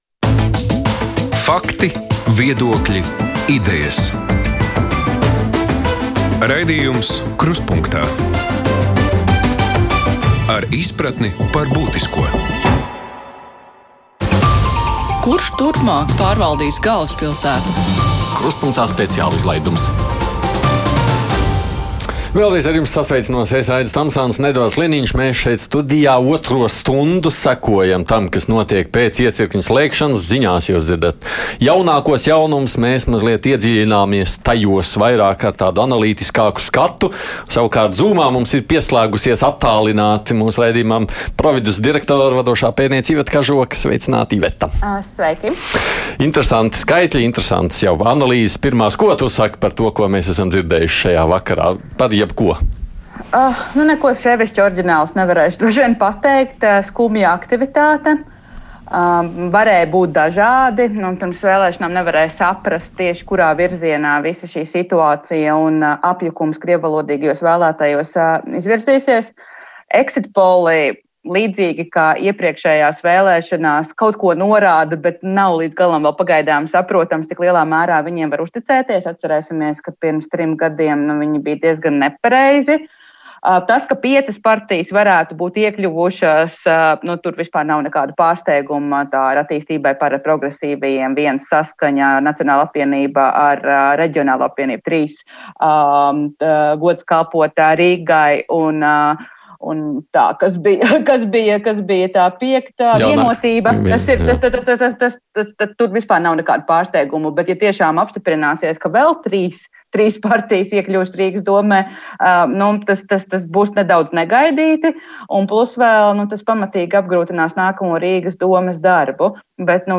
Vakarā pēc vēlešanu iecirkņu slēgšanas, kad sākusies balsu skaitīšana, diskutējam ar ekspertiem, sazvanāmies ar politiķiem un uzklausām klausītāju domas.